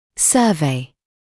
[‘sɜːveɪ][‘сёːвэй]обзор; обозрение; обследование; исследование; проводить обзор; исследовать